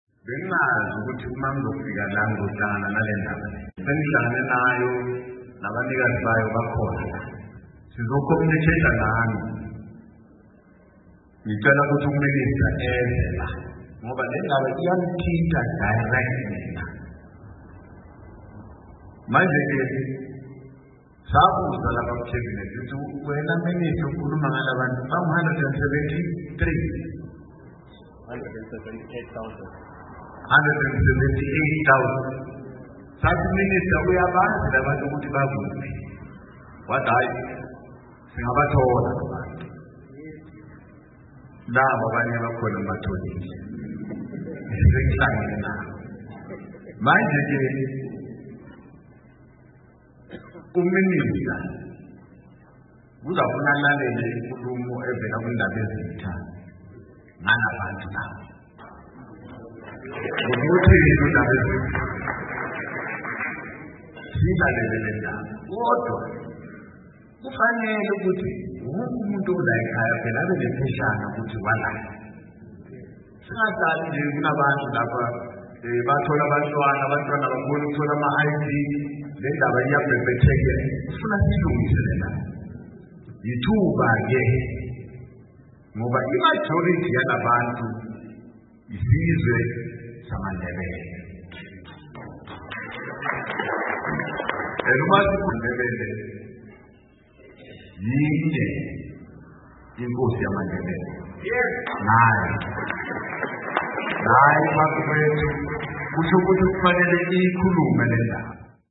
Okwethulwe Ngumsekeli Kamongameli uMnu. David Mabuza